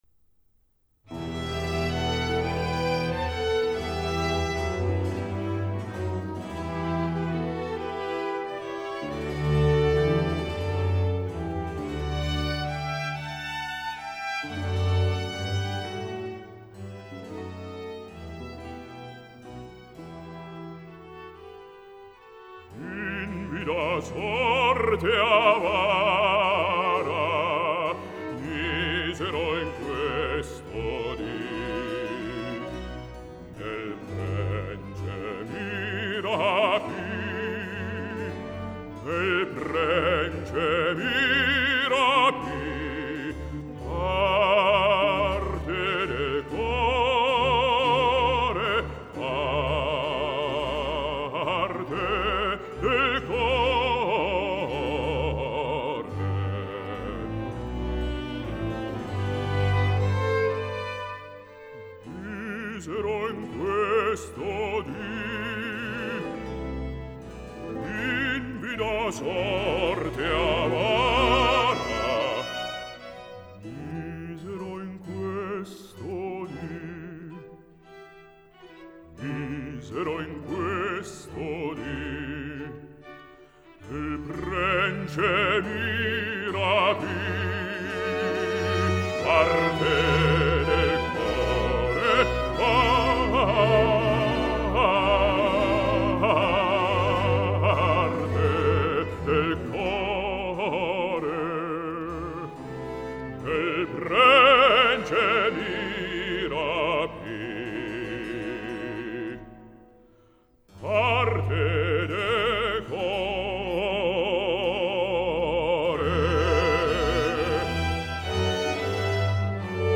el baix-baríton italià